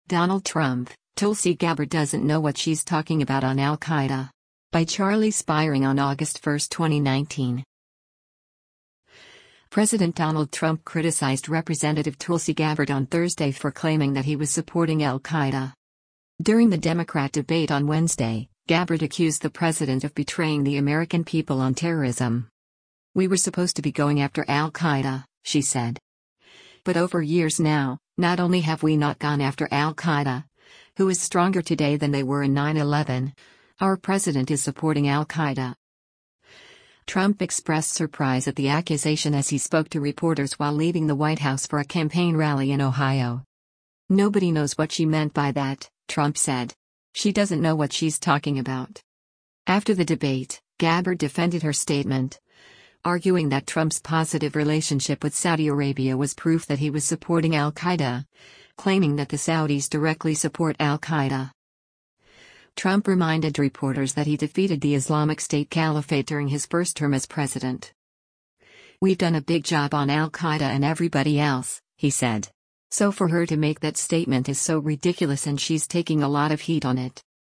Trump expressed surprise at the accusation as he spoke to reporters while leaving the White House for a campaign rally in Ohio.